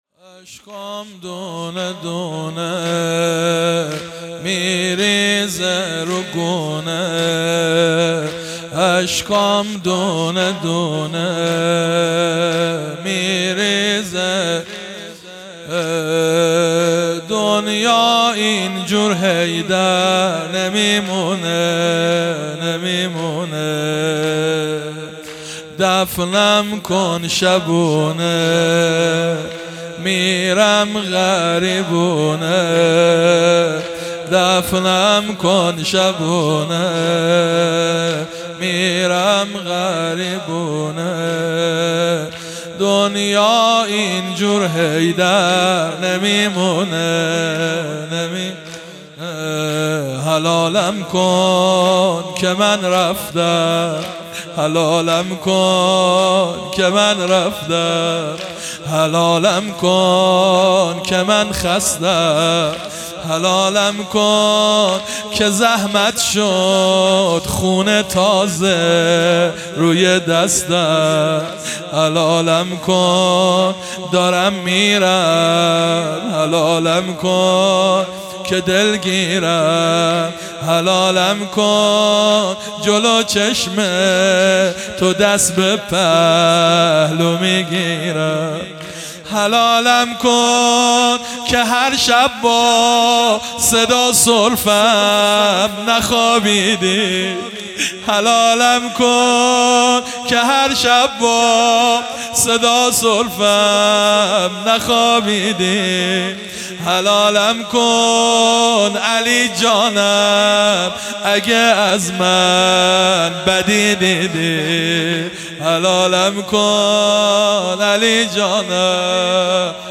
هیئت انصار ولایت دارالعباده یزد
زمینه - اشکام دونه دونه